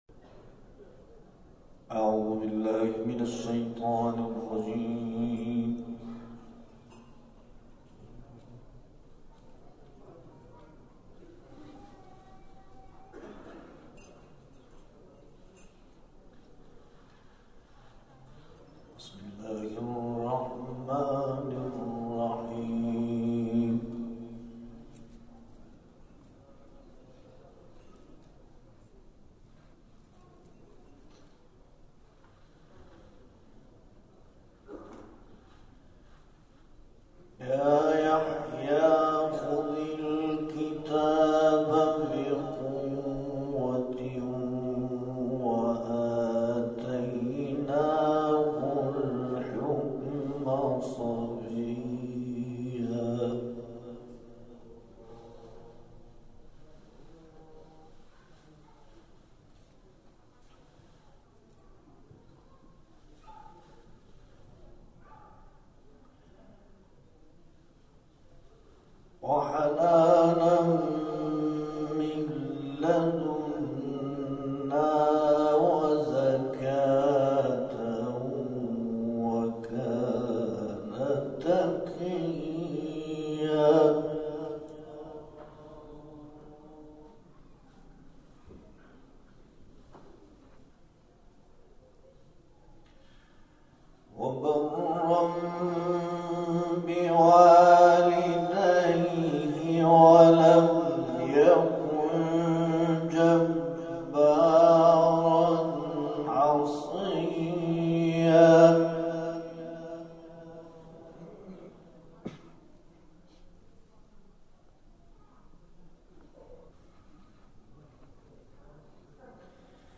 گروه جلسات و محافل: محفل انس با قرآن کریم، شب گذشته 13 آبان ماه، در امامزاده جعفر بن موسی الکاظم(ع) پیشوا برگزار شد.
به گزارش خبرگزاری بین‌المللی قرآن(ایکنا)، محفل انس با قرآن کریم، شب گذشته 13 آبان ماه، در امامزاده جعفر بن موسی الکاظم(ع)، پیشوا، بعد از نماز مغرب و عشاء با حضور قاریان و حافظان شهرستان پیشوا برگزار شد.